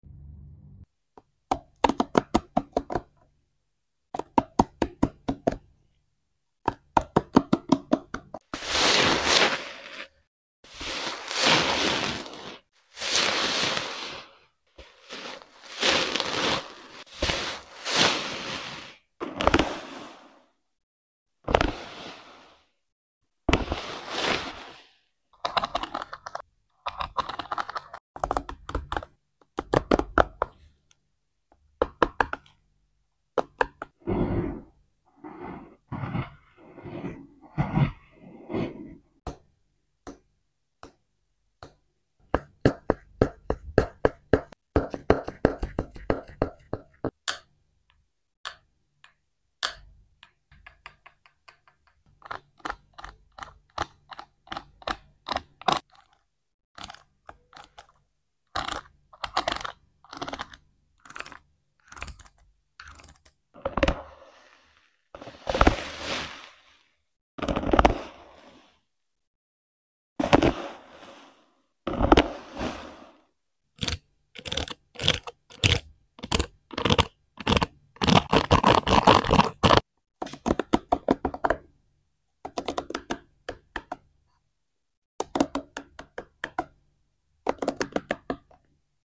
Gentle wood comb whispers. Pampering sound effects free download
Pampering Mp3 Sound Effect Gentle wood comb whispers. Pampering your ears.